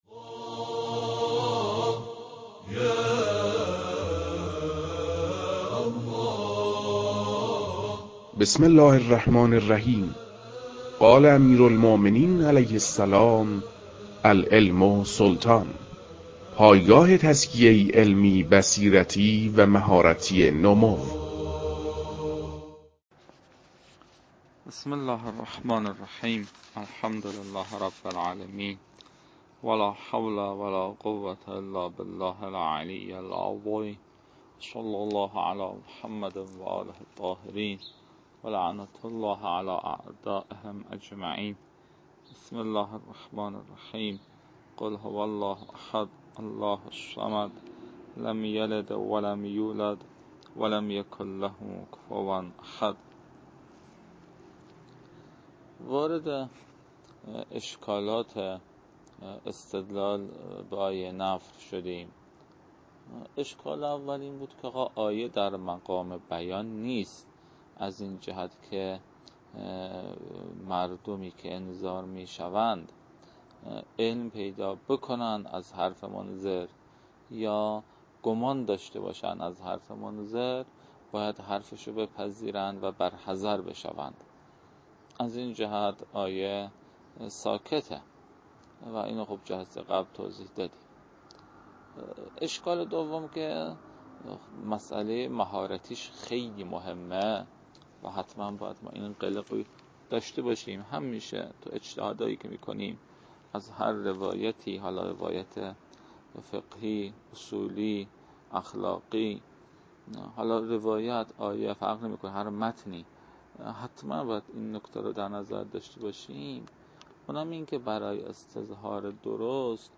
در این بخش، فایل های مربوط به تدریس مبحث رسالة في القطع از كتاب فرائد الاصول